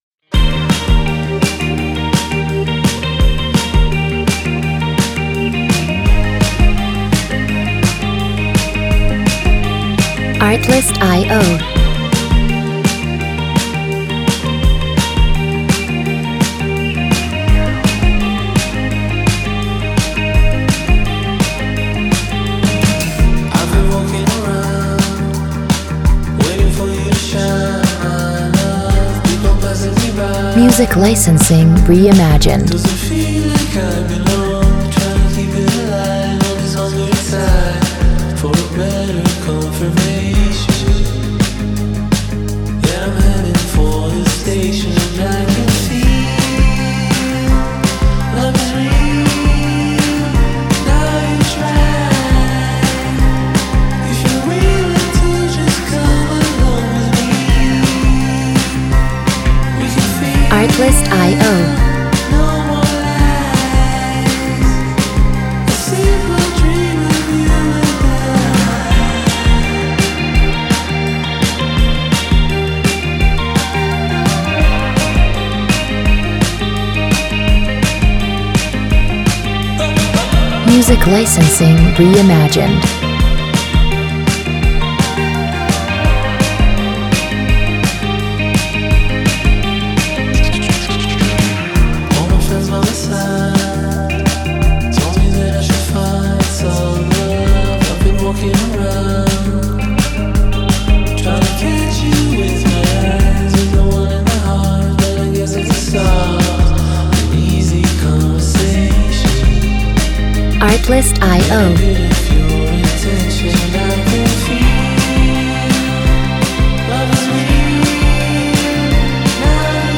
laidback, folk-pop tracks